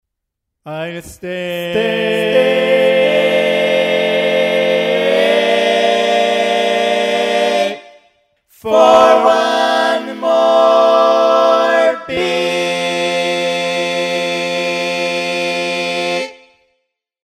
Key written in: F Major
How many parts: 4
Type: Barbershop
All Parts mix: